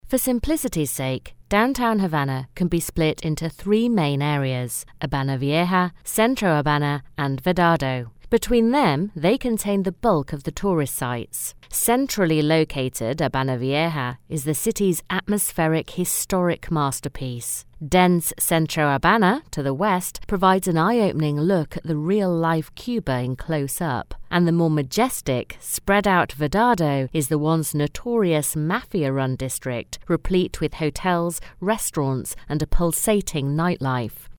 Smooth, Warm, Friendly, Professional British Voice.
englisch (uk)
Sprechprobe: Industrie (Muttersprache):
Professional Voiceover Artist.